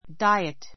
diet 1 A2 dáiət ダ イエ ト 名詞 ❶ 日常の食物 a well-balanced diet a well-balanced diet バランスの取れた食事 ❷ （病人・減量などのための） 規定食, ダイエット ⦣ ふつう食べる量を減らすことをいう.